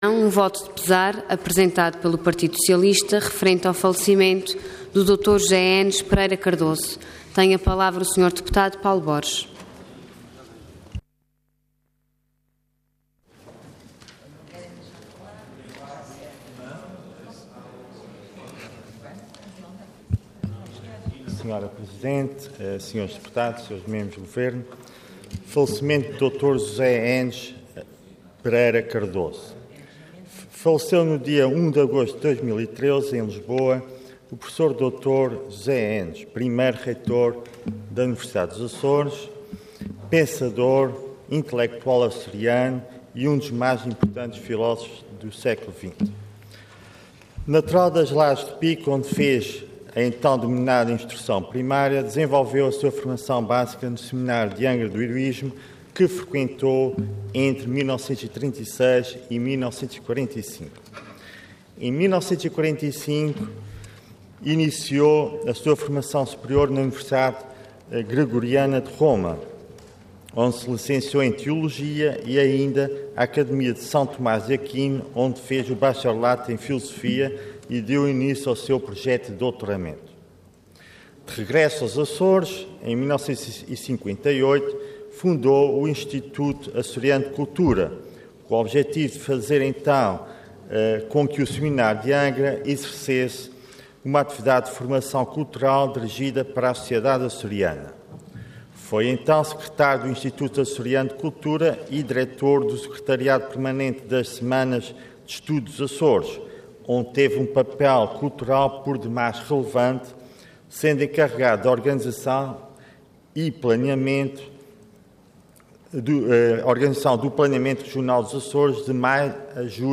Intervenção Voto de Pesar Orador Paulo Borges Cargo Deputado Entidade PS